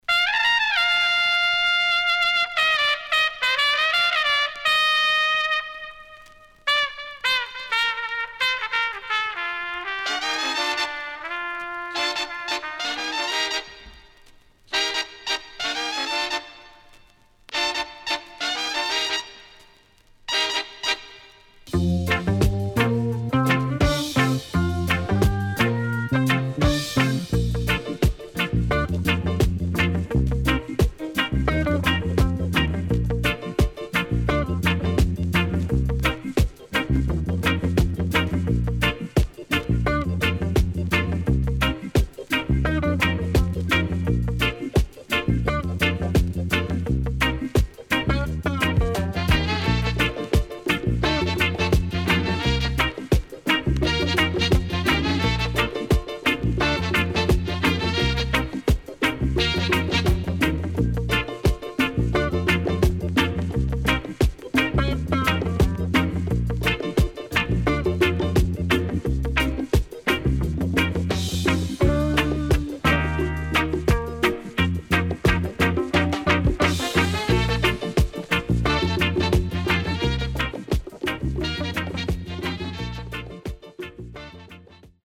【12inch】
SIDE A:うすいこまかい傷ありますがノイズあまり目立ちません。